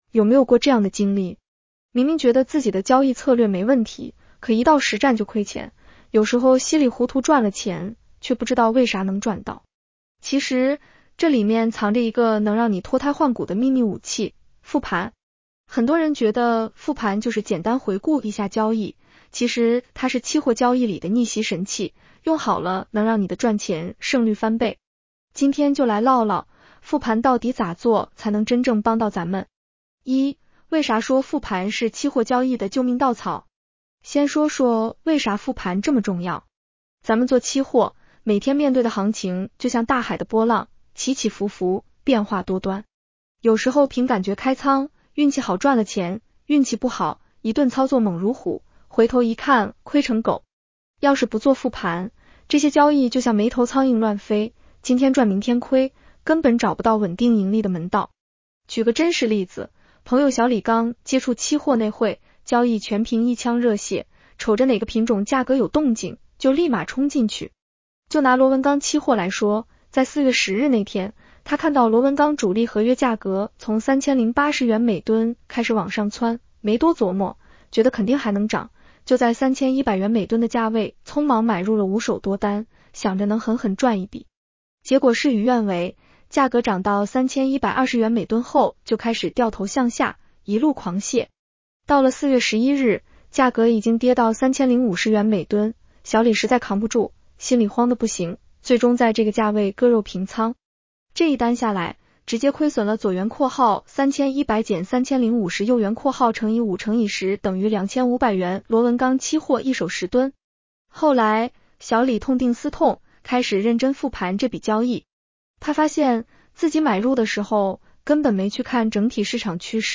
女声普通话版 下载mp3 有没有过这样的经历？